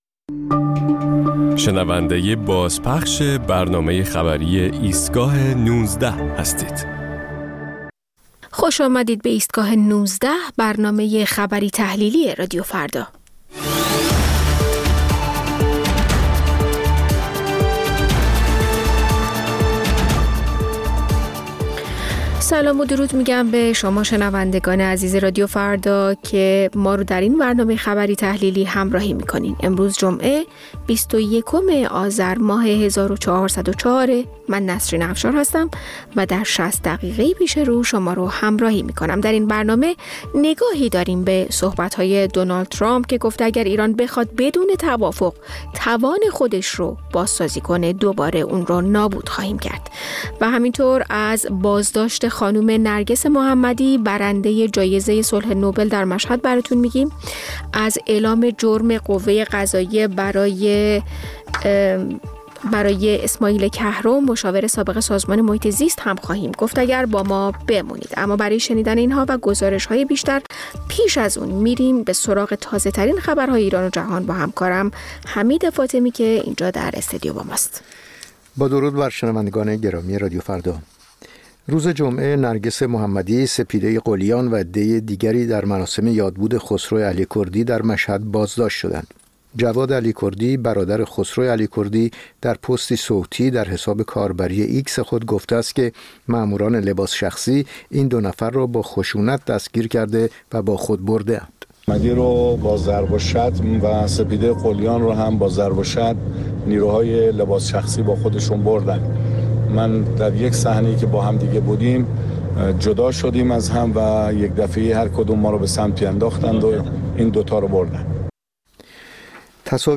مجموعه‌ای از اخبار، گزارش‌ها و گفت‌وگوها در ایستگاه ۱۹ رادیو فردا